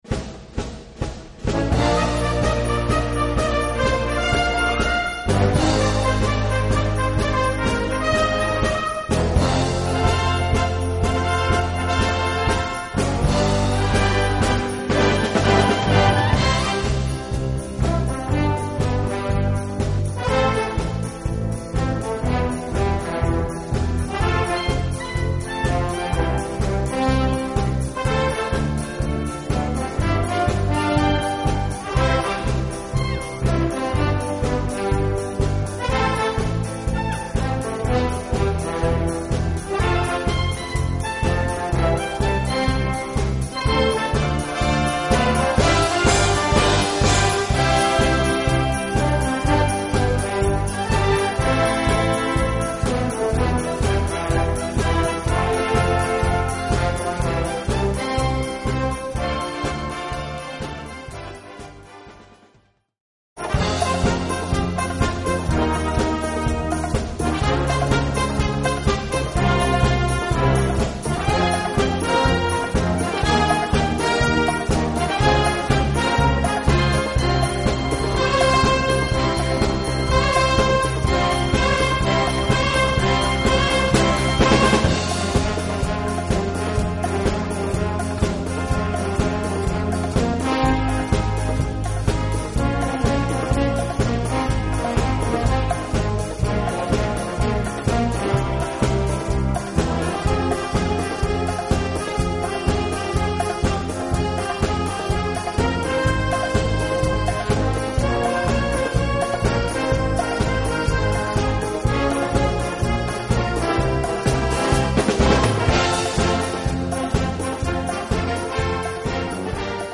Gattung: Potpourri modern
Besetzung: Blasorchester